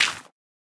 Minecraft Version Minecraft Version latest Latest Release | Latest Snapshot latest / assets / minecraft / sounds / block / composter / fill_success1.ogg Compare With Compare With Latest Release | Latest Snapshot
fill_success1.ogg